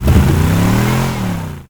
car-engine-load-1.ogg